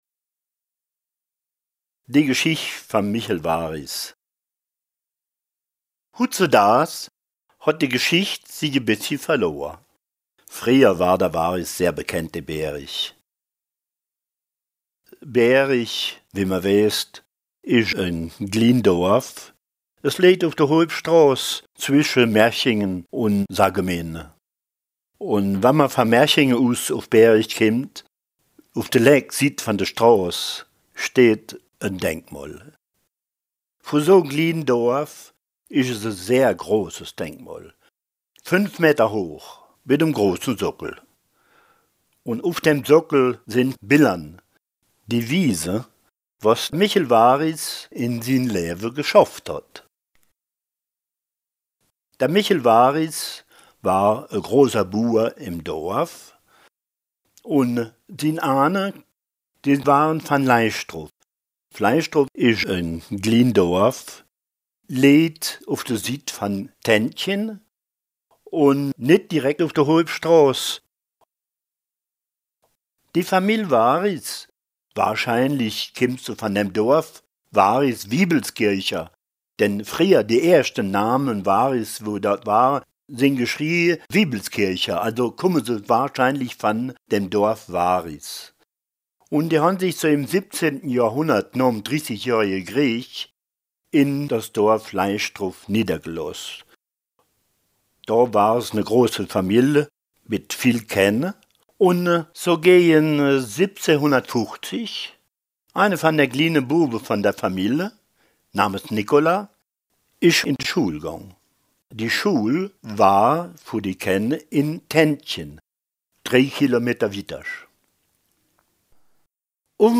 Contes et récits en ditsch enregistrés dans les communes de Racrange, Vallerange, Bérig-Vintrange, Harprich, Eincheville, Viller-Béning, Viller, Viller-Boustroff et de Boustroff.